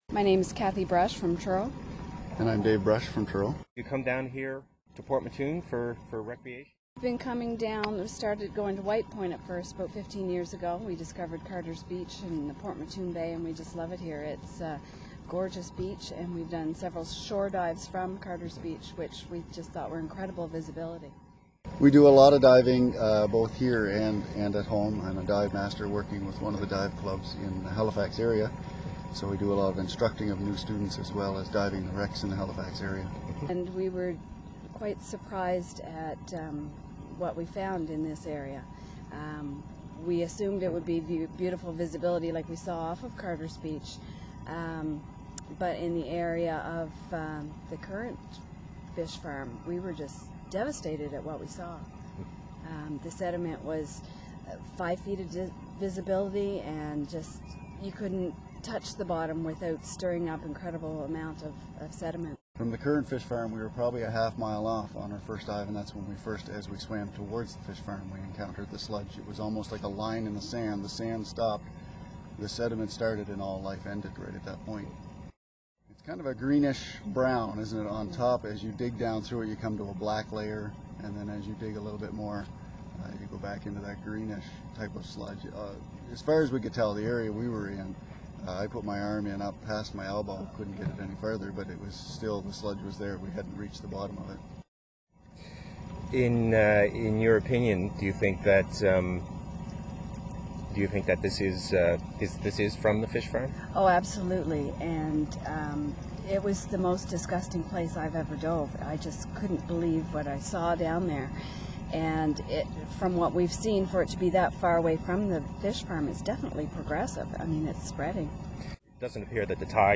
Interview with Divers